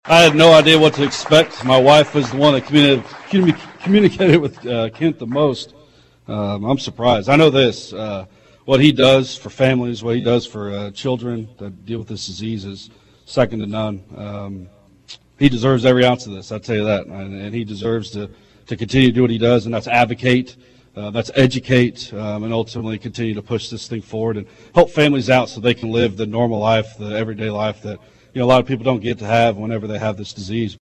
There were signs of a massive Match Day before Tuesday’s official check presentation ceremony — but most people on hand were totally shocked at the individual check amounts and the final total announced at Flinthills Mall as part of live coverage on KVOE.